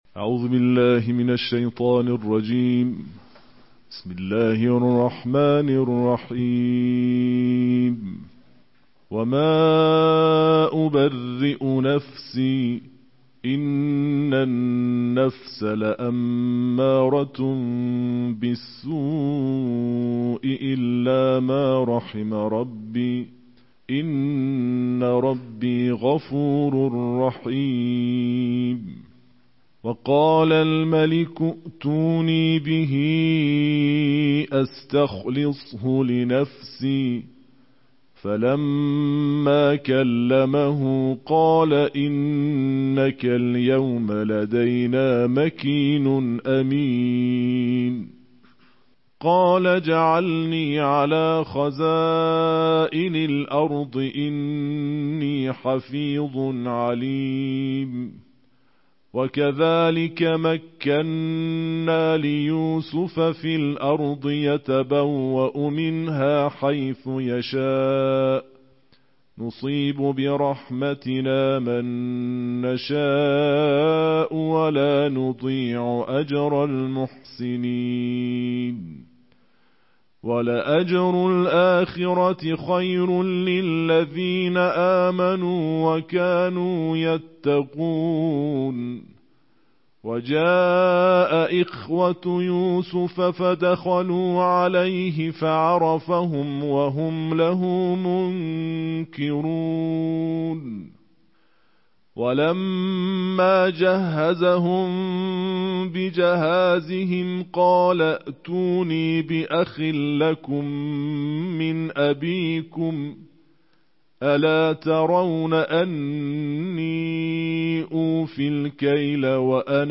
نړیوال قارِيان ،د قرآن کریم د دیارلسمې(۱۳) سپارې یا جزوې د ترتیل قرائت